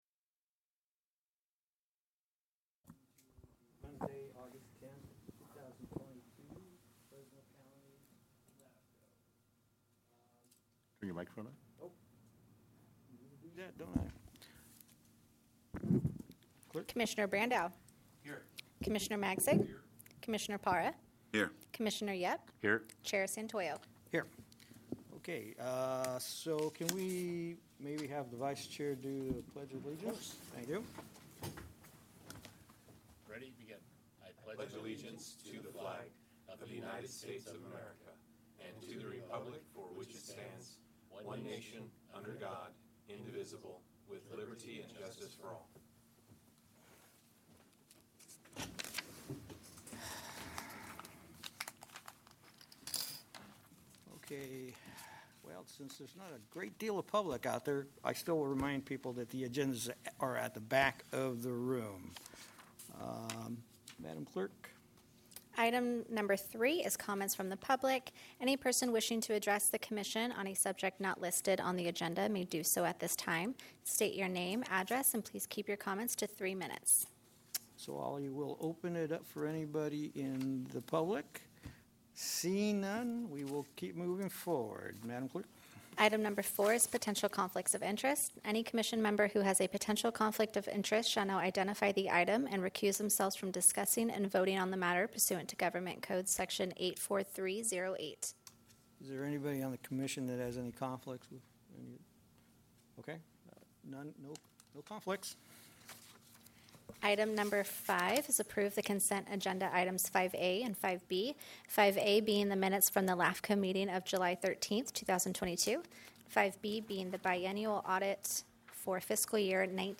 LAFCO Commission Hearing